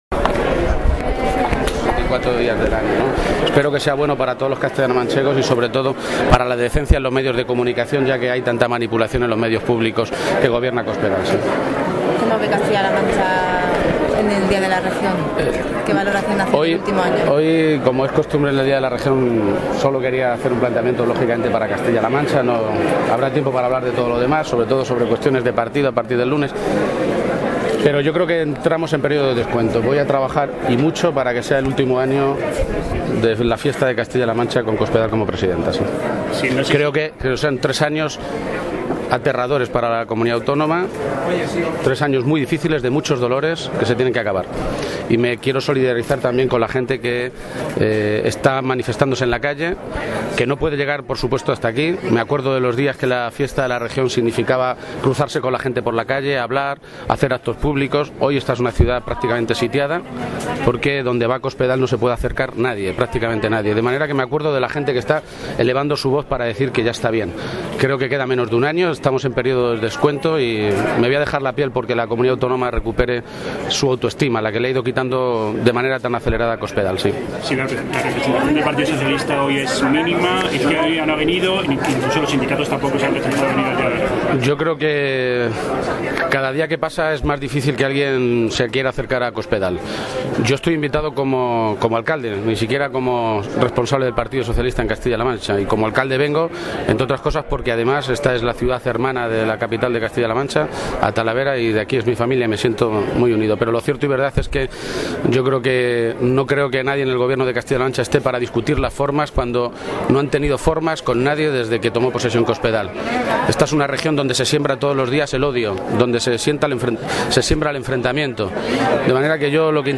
De esta manera se dirigía a los medios hoy el secretario general del PSOE de Castilla-La Mancha, Emiliano García-Page, a su llegada al talaverano Teatro Palenque, donde se ha celebrado el acto institucional del Día de Castilla-La Mancha de este año.
Cortes de audio de la rueda de prensa